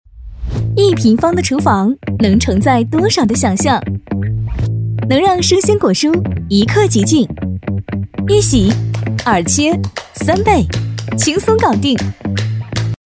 女B32-大气质感 高端大气